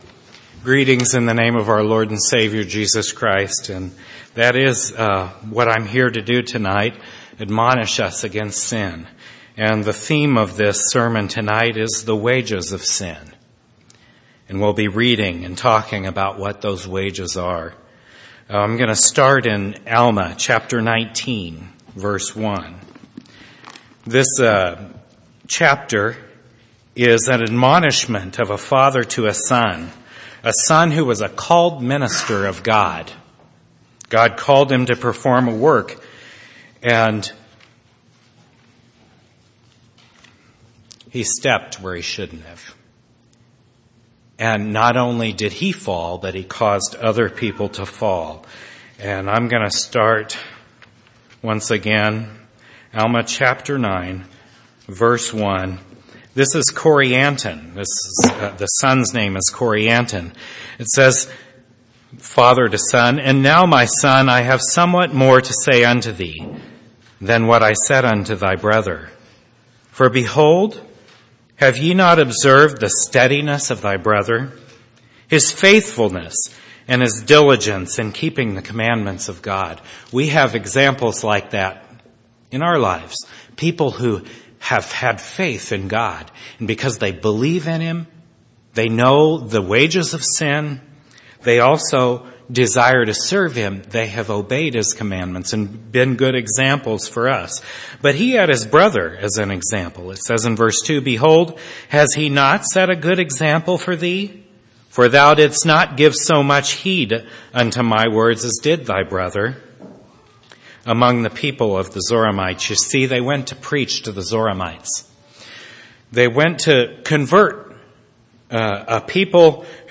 4/26/2009 Location: Temple Lot Local Event